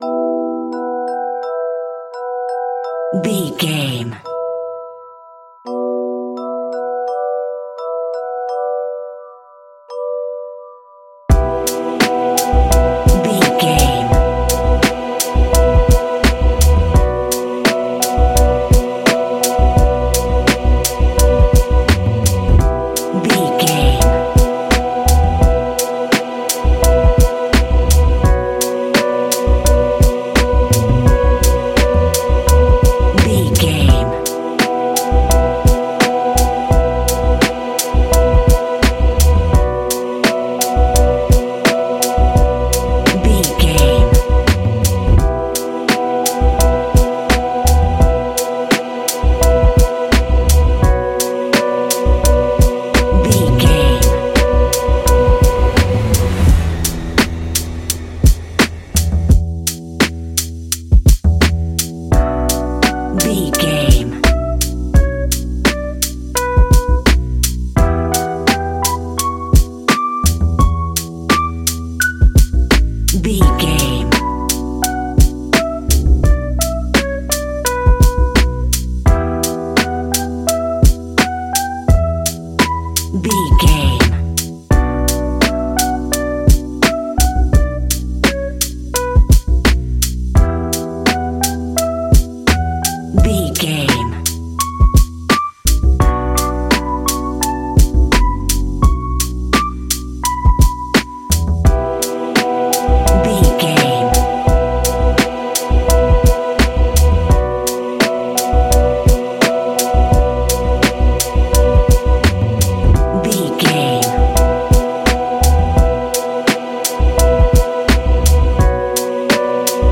Ionian/Major
B♭
chilled
laid back
Lounge
sparse
new age
chilled electronica
ambient
atmospheric